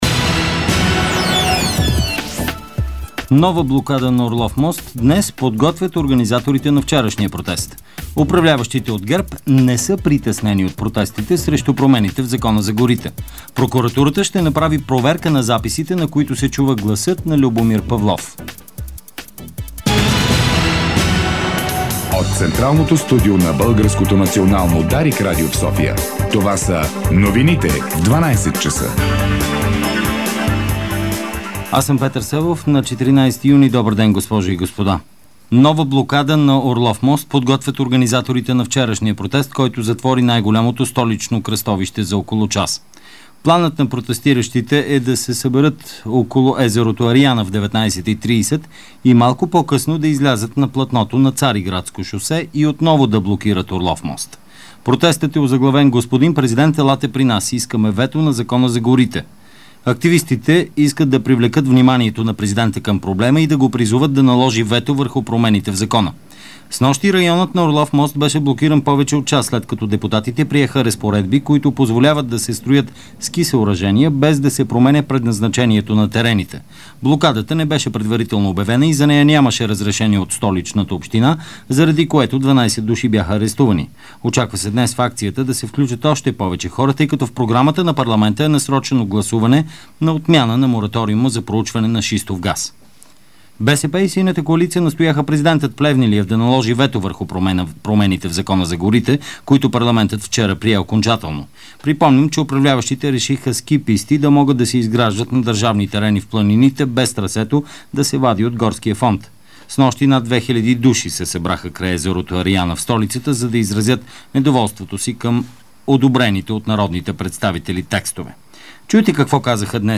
Обедна информационна емисия - 14.06.2012